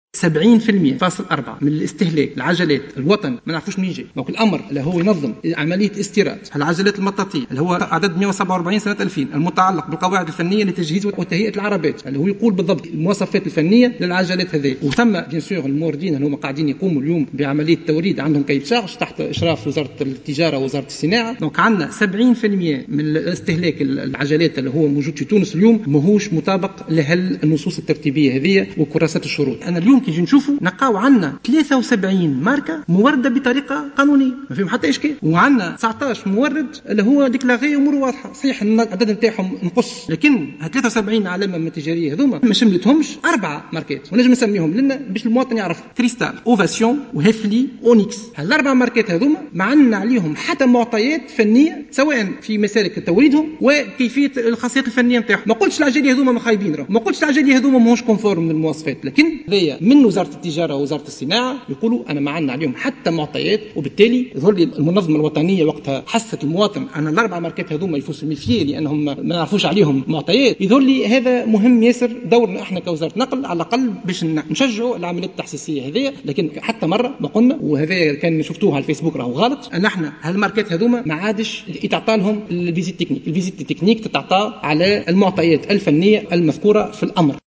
وأوضح شقشوق، خلال جلسة عامة بمجلس نواب الشعب، خُصّصت لتوجيه أسئلة شفاهية، أنّ تونس تضم 19 مورّدا للعجلات المطاطية و73 علامة تجارية مورّدة بطريقة قانونية وفقا للأمر المنظم لهذه العملية (عدد 147 المتعلق بالقواعد الفنية لتجهيز وتهيئة العربات) والذي يُحدد المواصفات الفنية للعجلات.